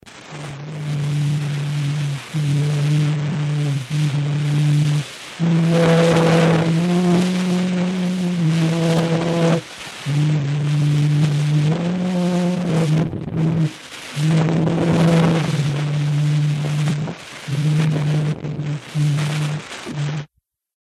En mars 2008, des scientifiques parviennent à restituer le son enregistré sur une bande de phonautographe : c’est la chanson « Au clair de la lune » qui peut-être écoutée ci-dessous (document Wikimédia).